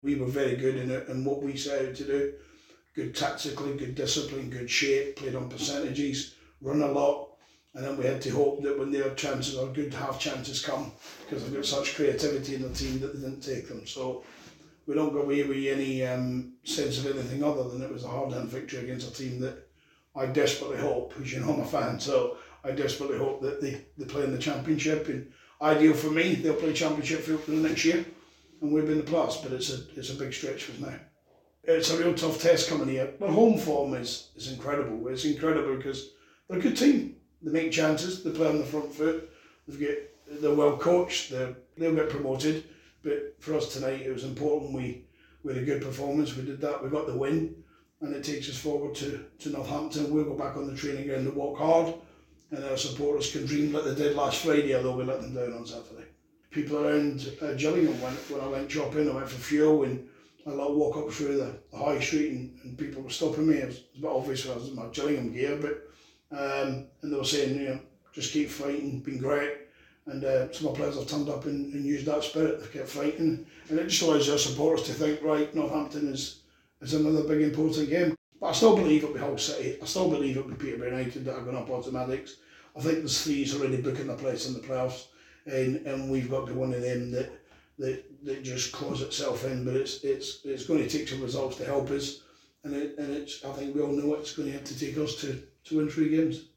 LISTEN: Gillingham manager Steve Evans spoke to us after their 1-0 victory at Peterborough - 21/04/2021